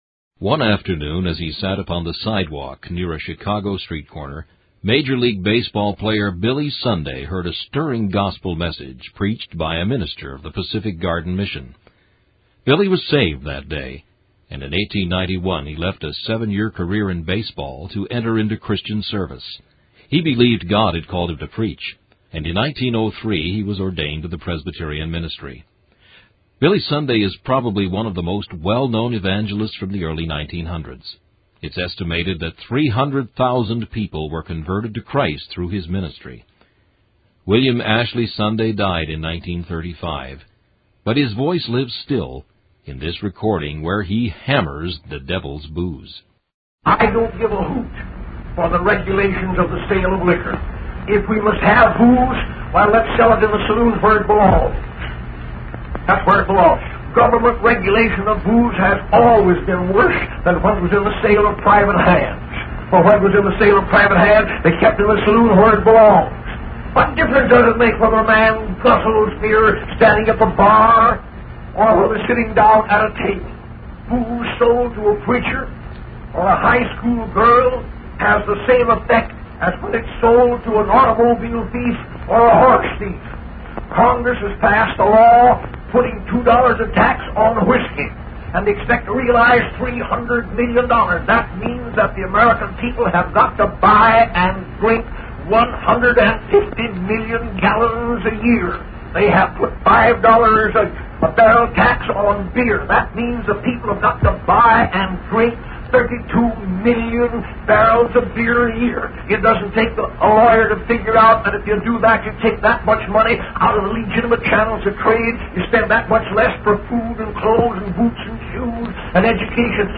Peebles Evangelical Church - Sermons